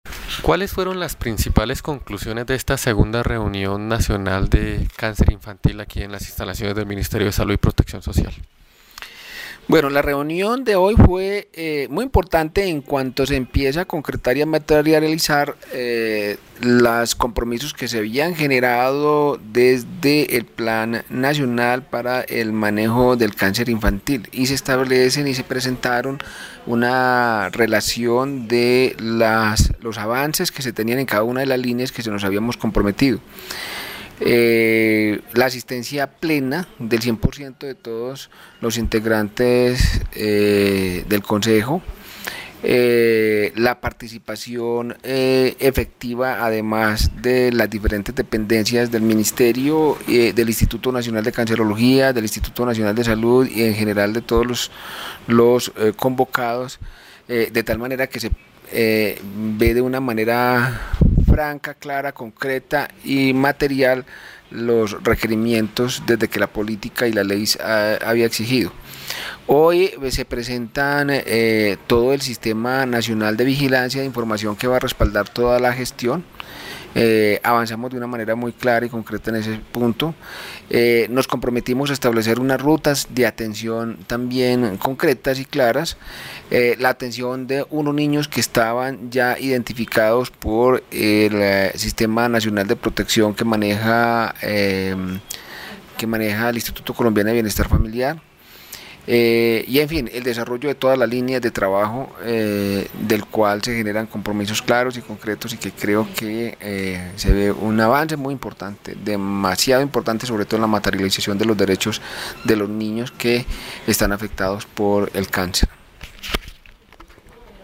Audio: Elkin Osorio, Director (e) de Promoción y Prevención, presenta las conclusiones de la sesión del Consejo Nacional de Cáncer Infantil.